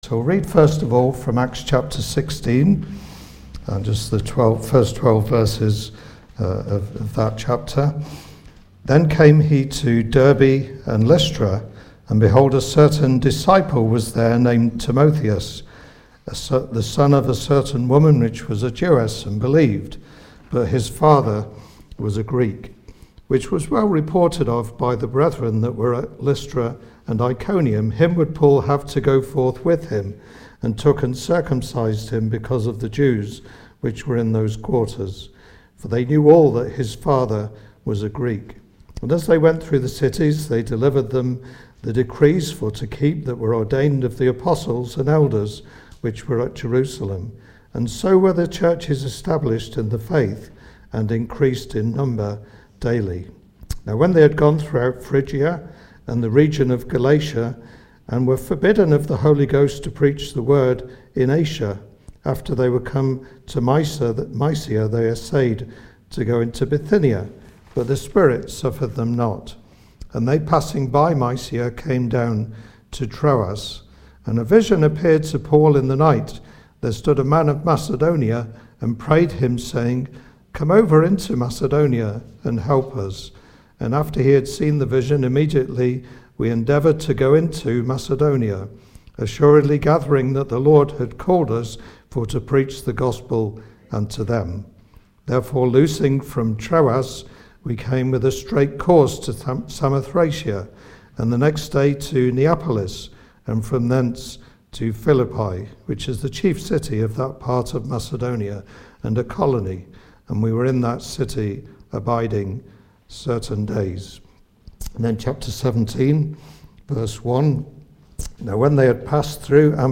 Service Type: Ministry